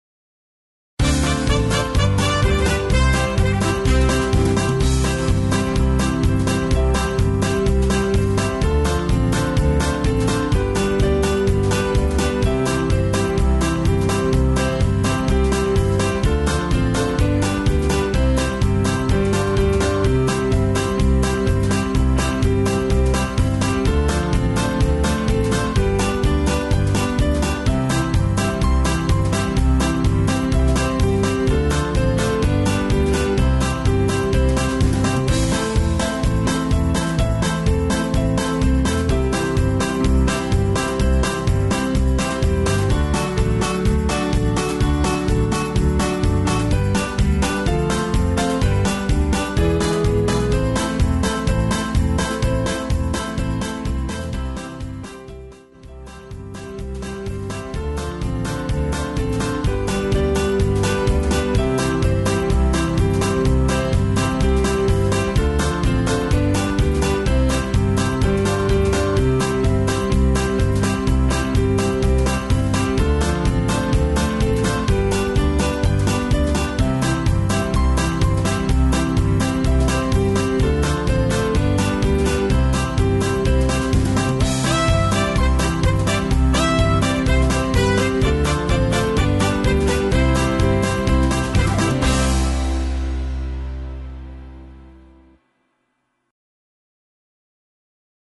Rhythm Track